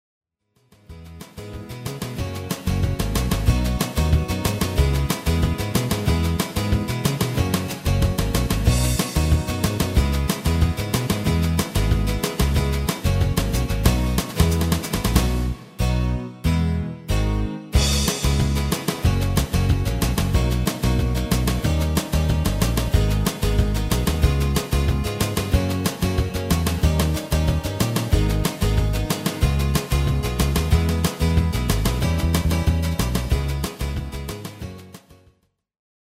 Demo/Koop midifile
Genre: Nederlands amusement / volks
- Géén tekst
- Géén vocal harmony tracks